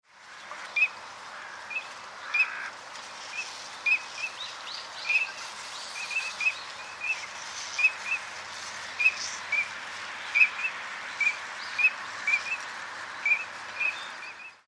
kogamo_c1.mp3